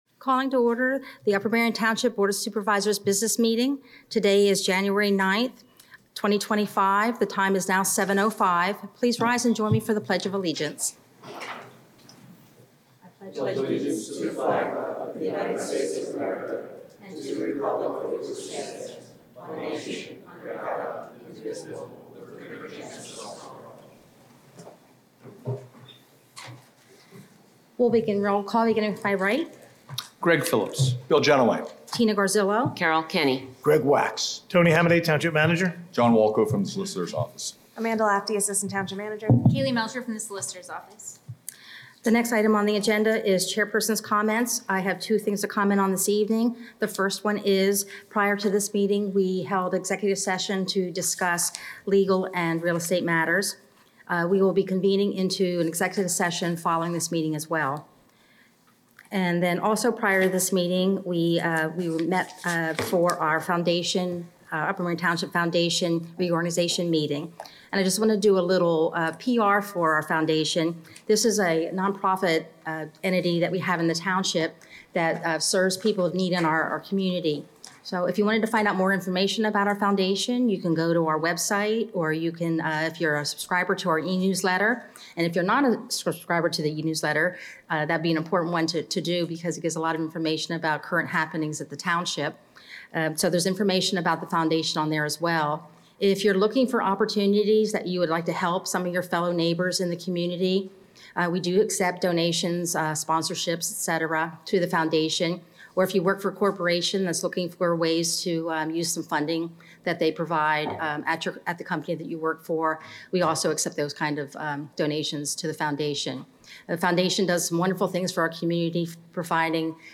BOS Meeting - January 9, 2025